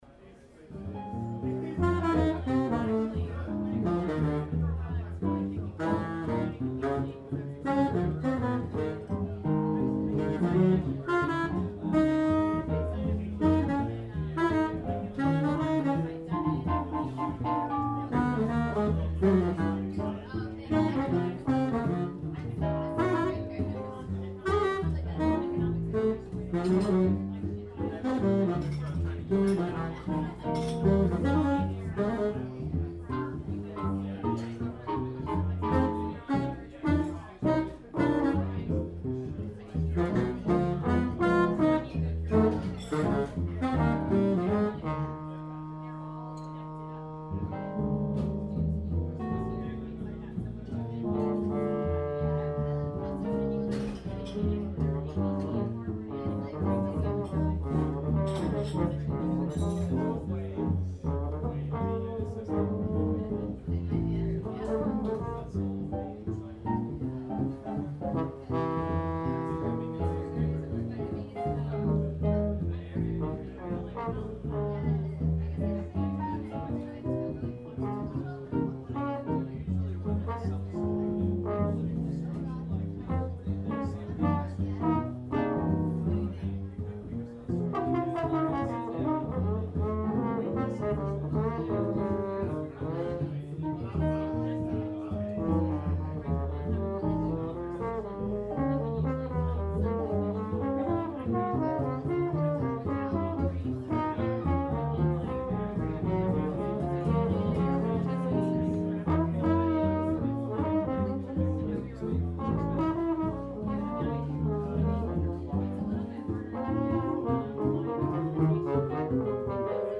bari sax
trombone
piano
bass
Filed under Jazz